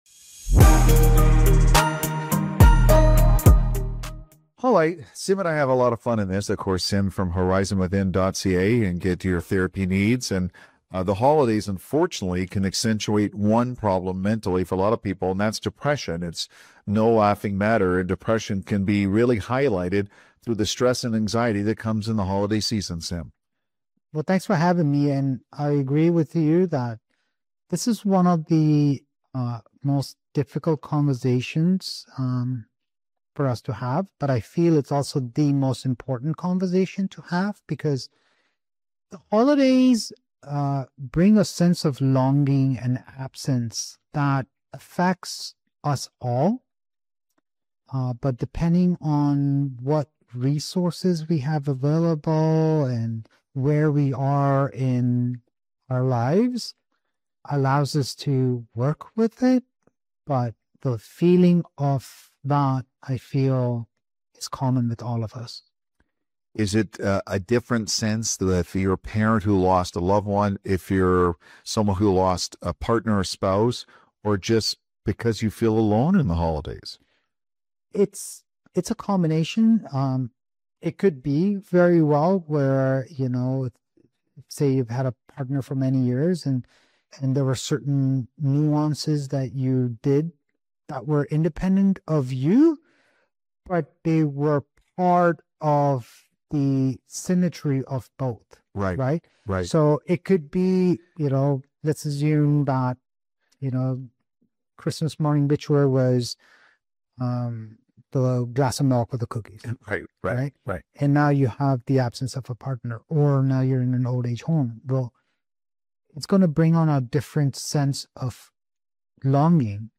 In this powerful conversation, we unpack why the holidays hit so hard and how to recognize depression beneath the surface.